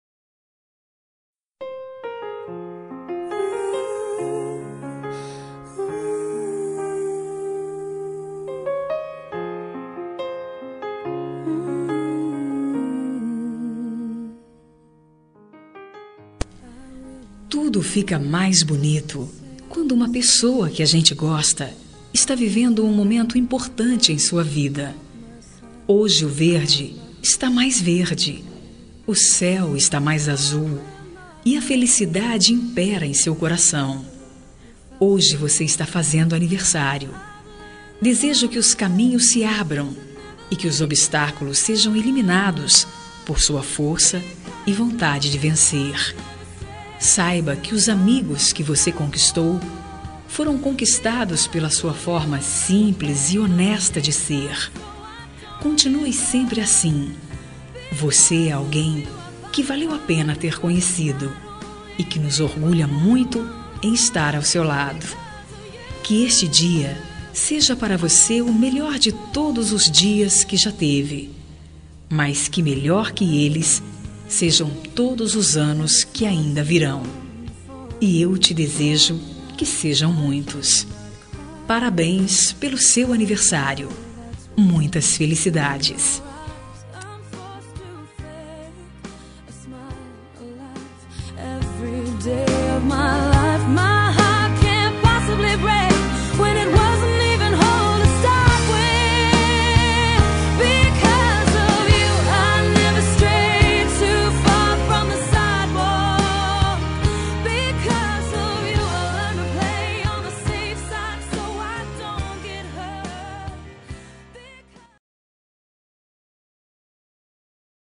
Aniversário de Pessoa Especial – Voz Feminina – Cód: 1891